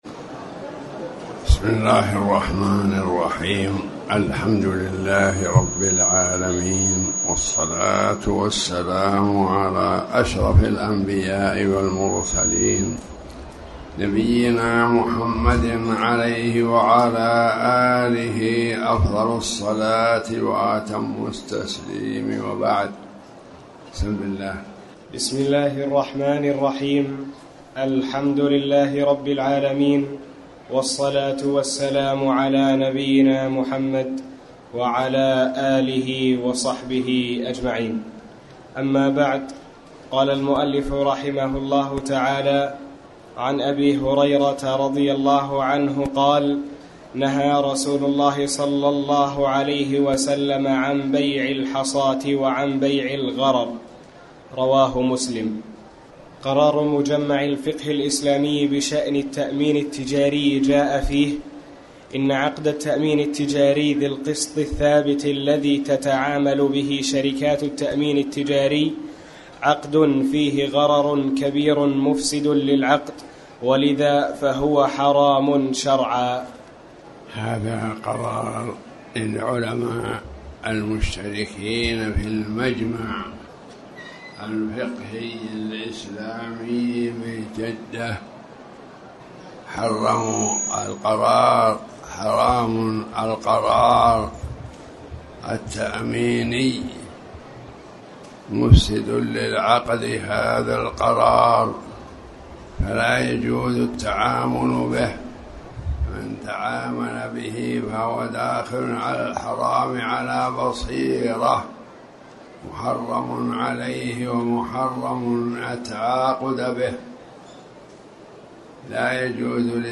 تاريخ النشر ٨ رجب ١٤٣٩ هـ المكان: المسجد الحرام الشيخ